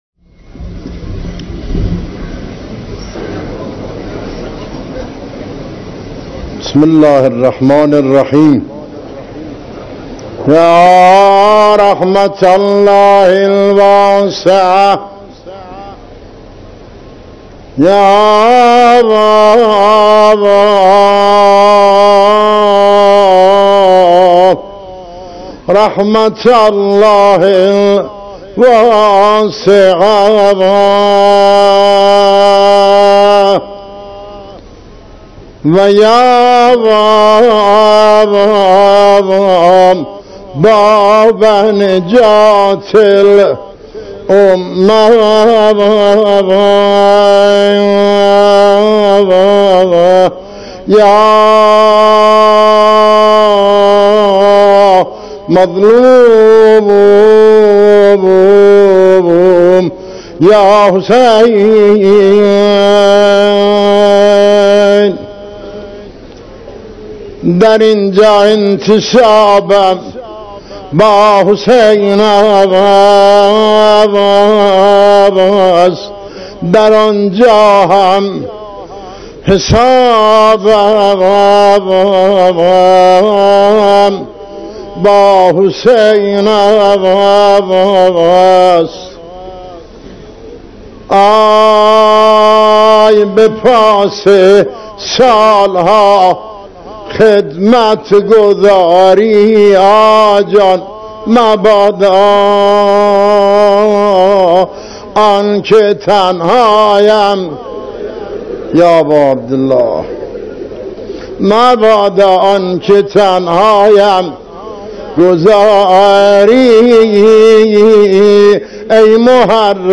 روضه‌خوانی
در اولین محرم پس از رحلت امام خمینی رحمه‌الله